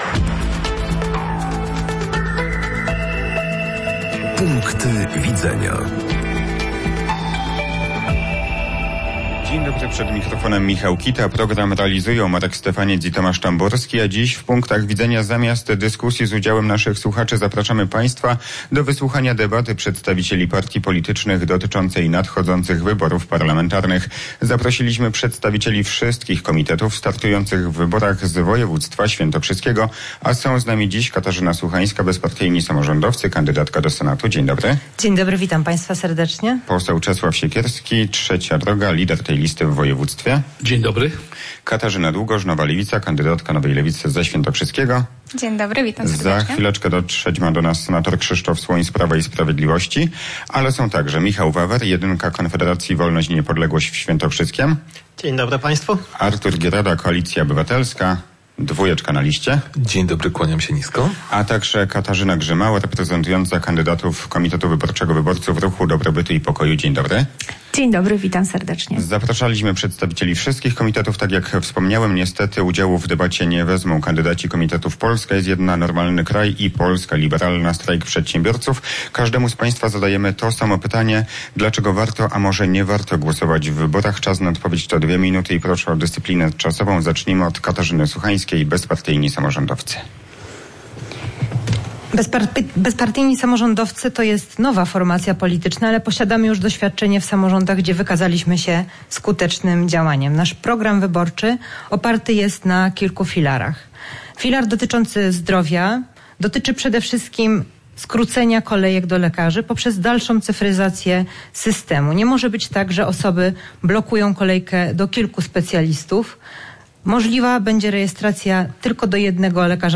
Punkty Widzenia. Debata przedstawicieli partii politycznych
Dziś (czwartek, 5 października) w Punktach Widzenia, zamiast dyskusji z udziałem słuchaczy zapraszamy Państwa do wysłuchania debaty przedstawicieli partii politycznych dotyczącej nadchodzących wyborów.